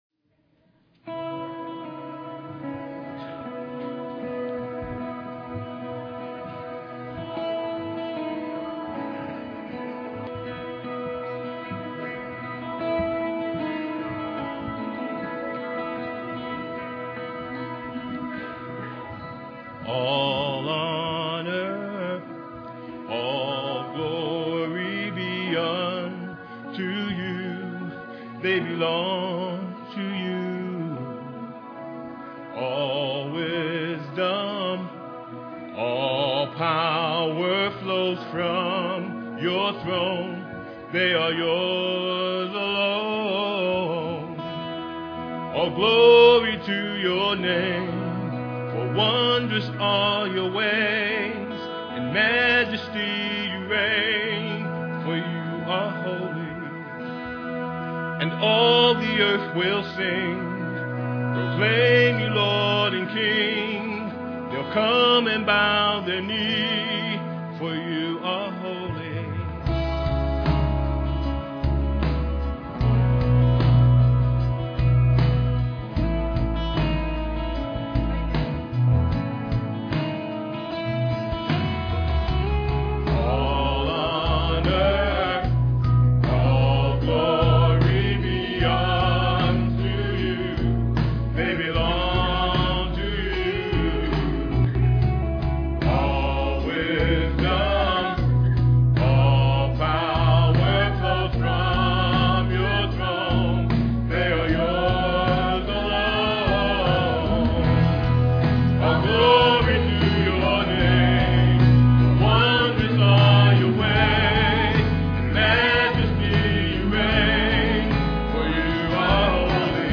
Scripture: Revelation, chapter 19, portions read